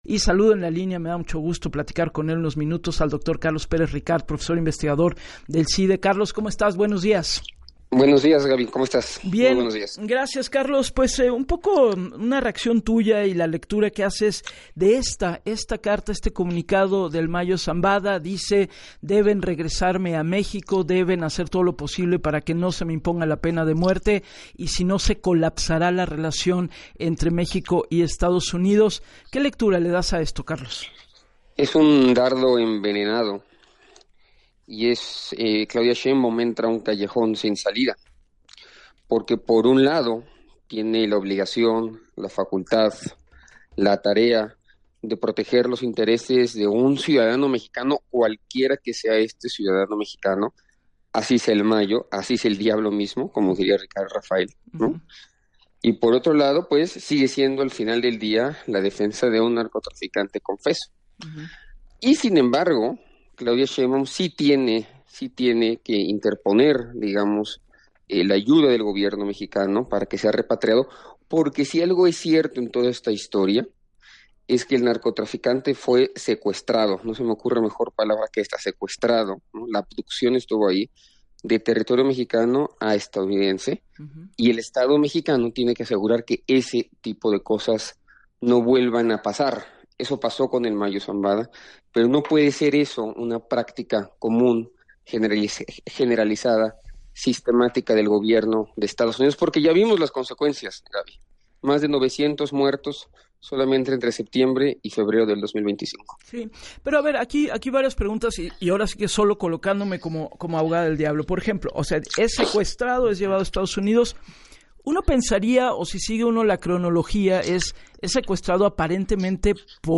“Por un lado, tiene la obligación de defender los derechos de cualquier ciudadano mexicano, cualquiera que sea, así sea el “El Mayo” o el diablo mismo, y, por otro lado, sigue siendo al final del día la defensa de un criminal confeso”, detalló en el espacio de “Así las Cosas” con Gabriela Warkentin.